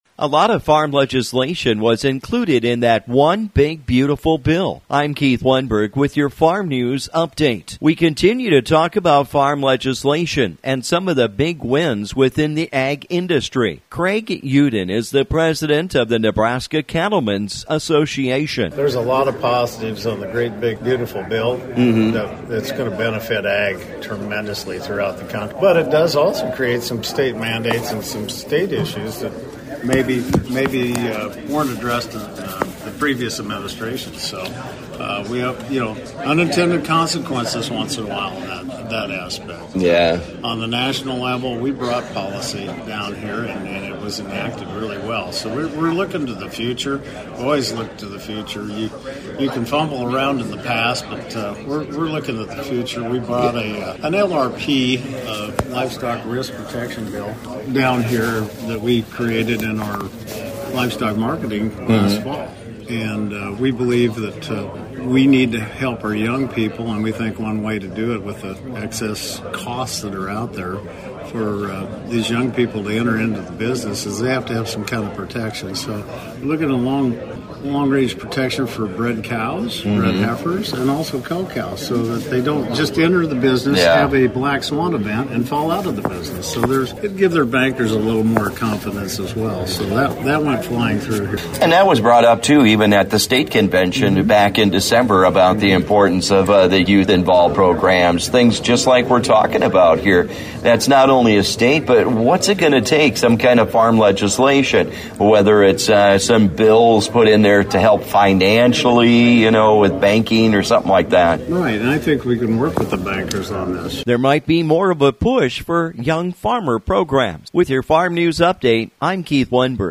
While attending Cattle Con last week, we dug into the benefits of having Farm Programs in place.